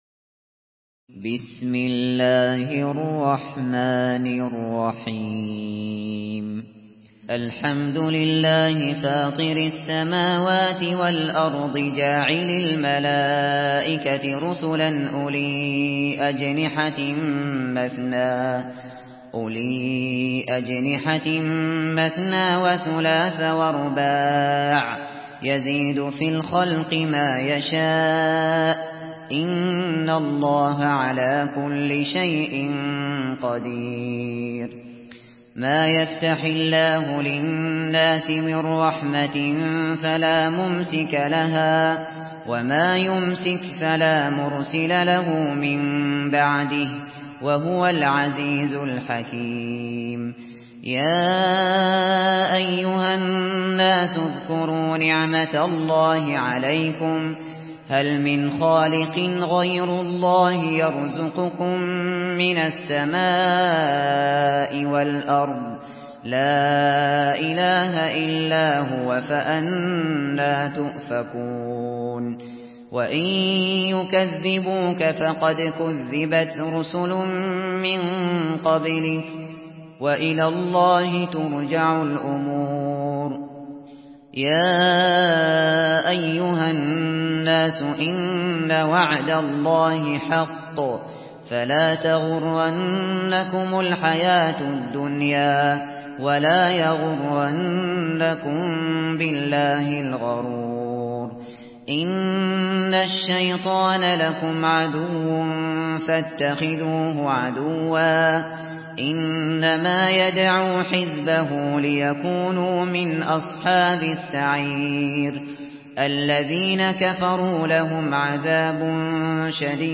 Surah Fatir MP3 in the Voice of Abu Bakr Al Shatri in Hafs Narration
Murattal